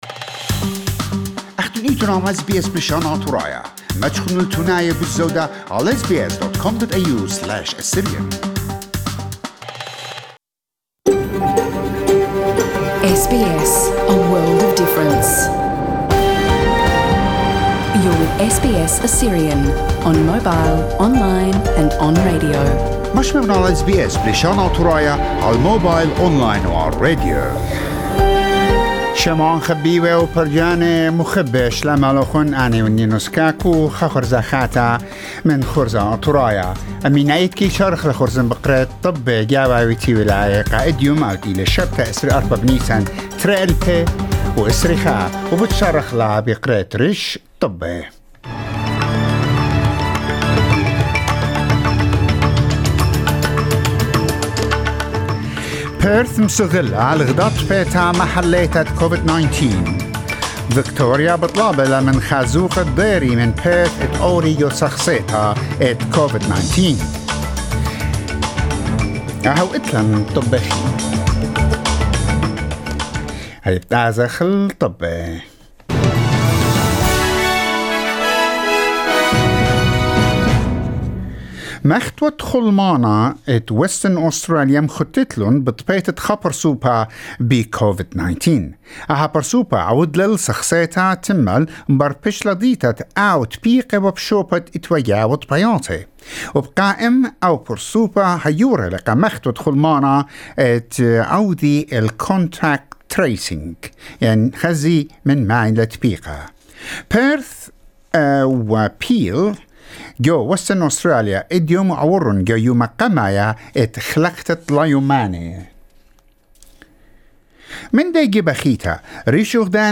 NEWS Saturday 24 April 2021